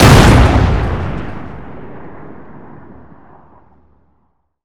Index of /server/sound/weapons/explosive_m67
m67_explode_3.wav